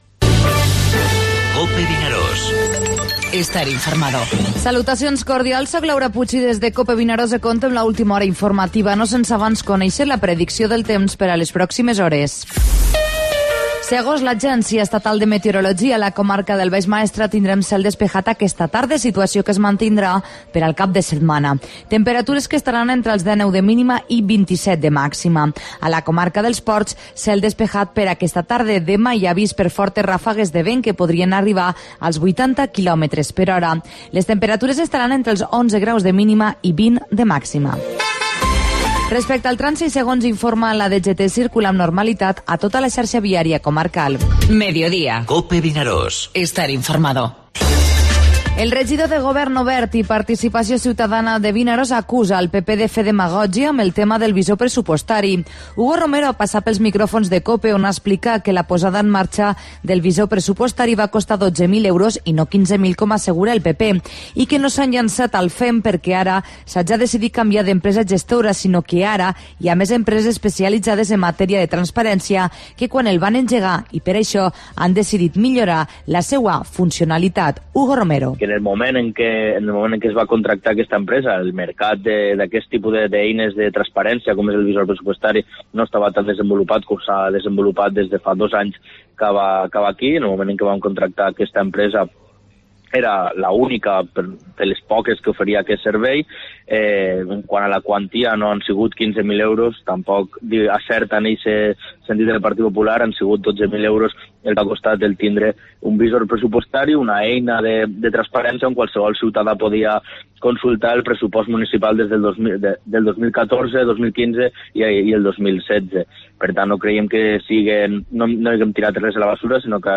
Informativo Mediodía COPE al Maestrat (01/09/2017)